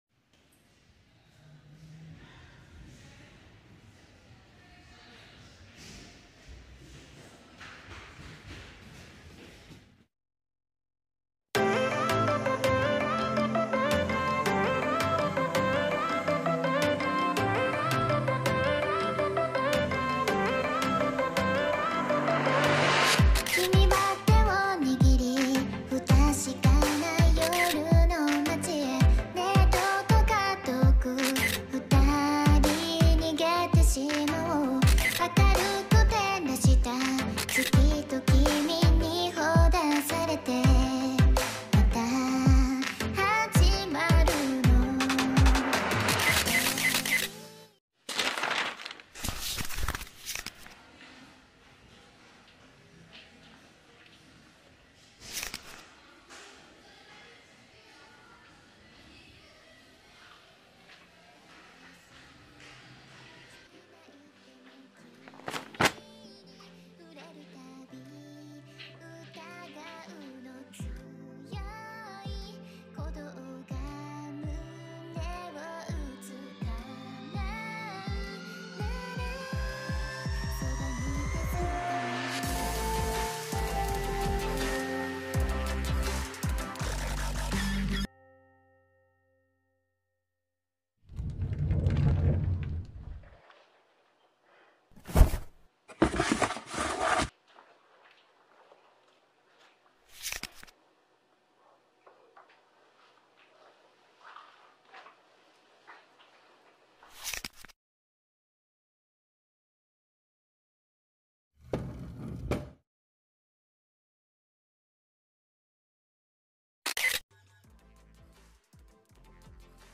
「恋愛観測」【声劇台本】